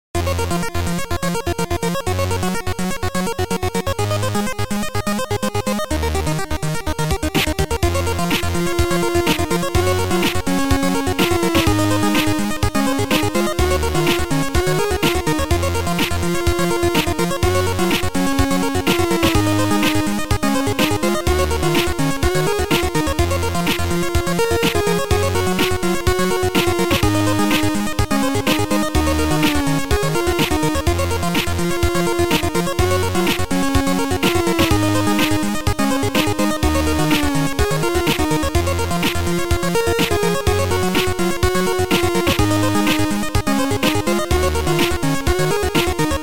Sound Format: Noisetracker/Protracker
Chip Music